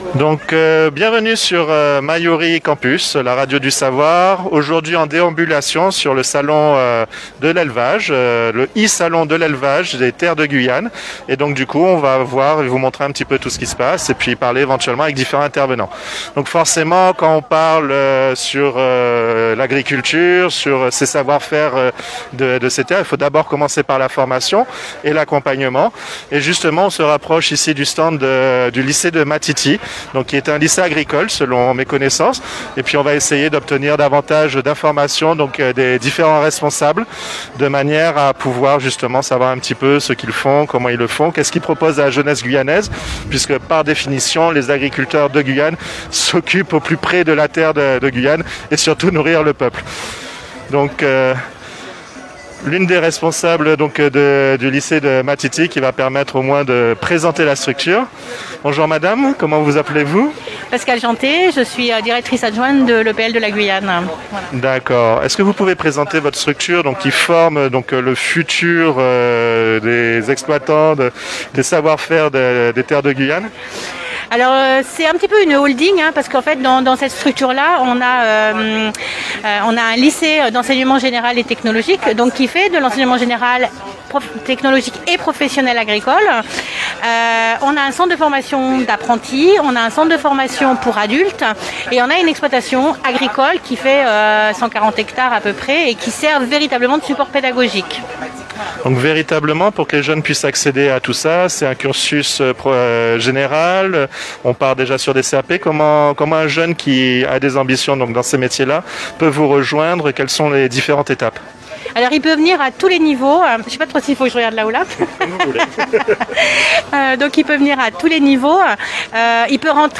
Les exposants de la première édition du e-salon Guyane Terre d’élevage s'expriment sur Radio Mayouri Campus.